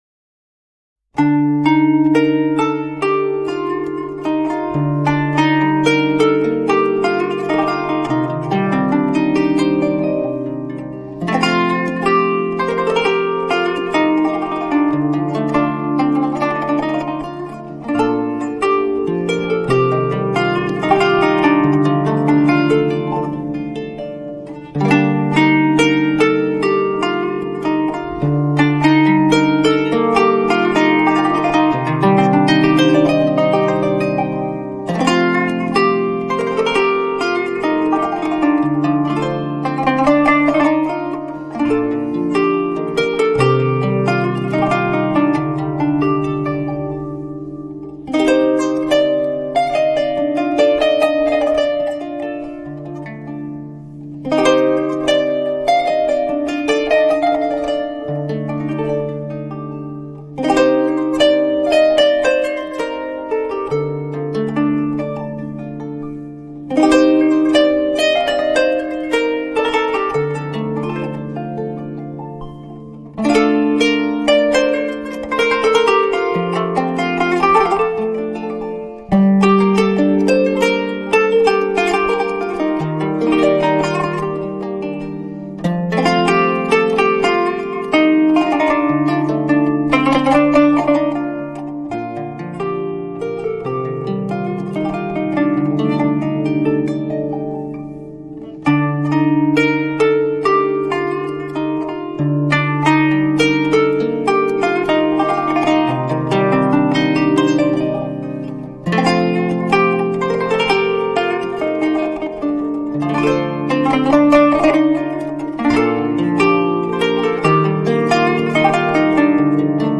قانون
تعداد سیم های قانون از 63 تا 84 تار متغیر است و نوع 72 سیمی آن متداول تر است که به صورت سه تایی باهم کوک می شوند و 24 صوت از آن به گوش می رسد که می توان گفت دامنه صوتی آن حدود 3 اکتاو می باشد.
ساز-قانون-موسیقی-۷۳.mp3